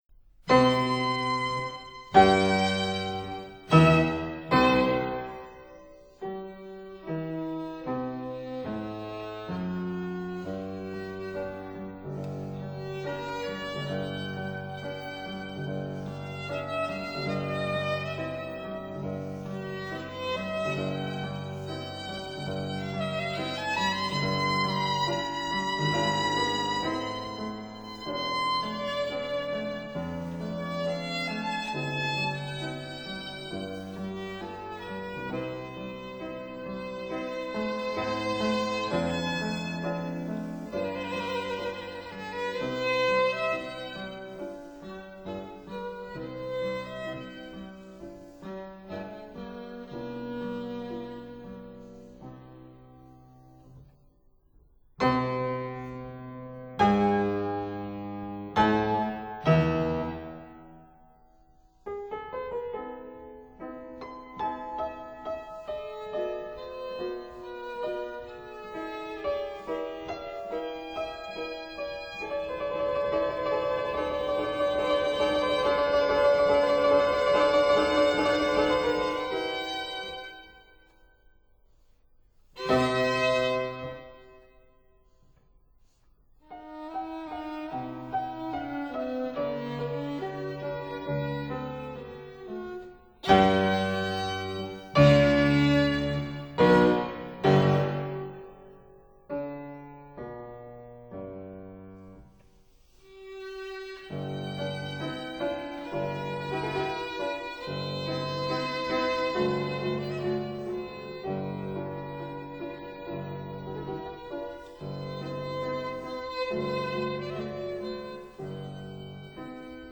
Trio in D major for Piano, Violin & Cello, VB 172
(Period Instruments)